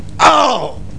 scream4.mp3